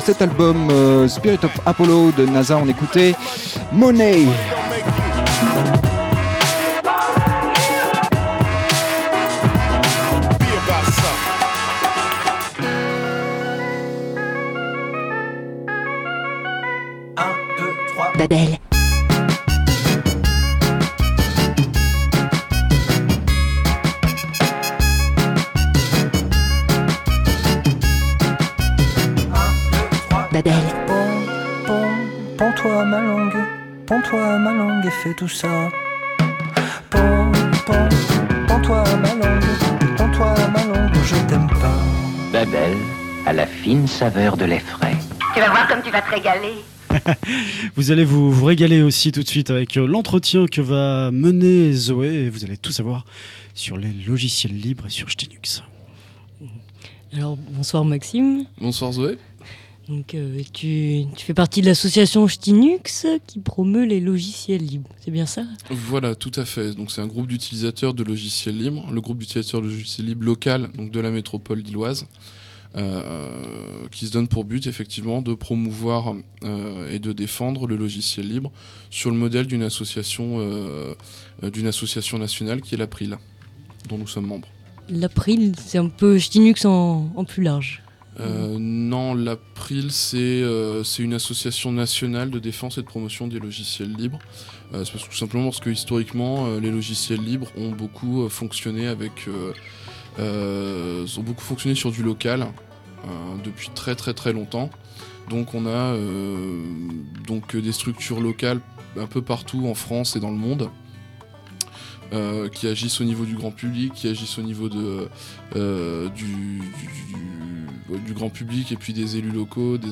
Il s'agit d'une interview d'une dizaine de minutes, qui passera en direct à la radio et sur le flux web, et qui sera filmée pour exploitation ultérieure (dans un magazine, j'ai pas tout compris, j'aurai plus de détails ce soir je pense). Pour l'émission (Babel), on me demande de présenter l'association dans le cadre de l'économie solidaire : il me faut donc a priori présenter brièvement le logiciel libre (dans sa dimension idéologique) et les activités de l'association.